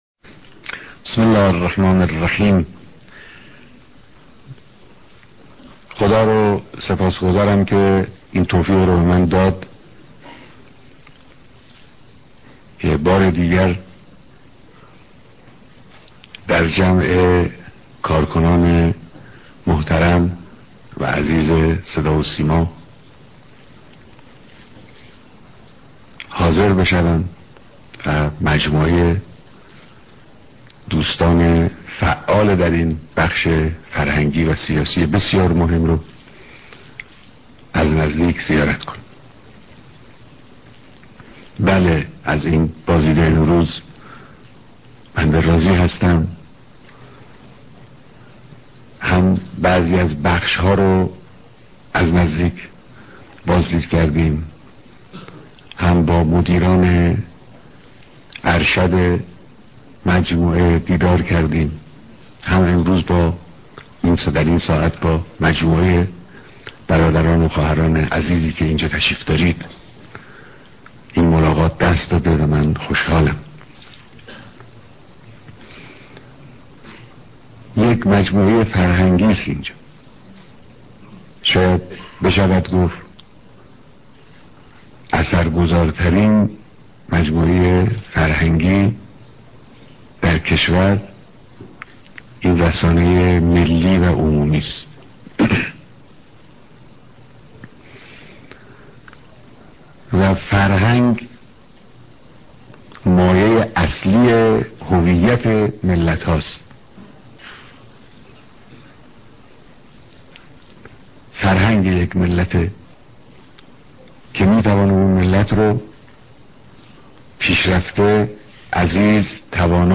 بيانات در جمع كاركنان سازمان صدا و سيما